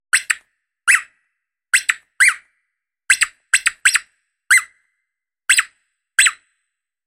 That's the squeaky sound of a dog toy
• Category: A dog toy (squeaker)
• Quality: High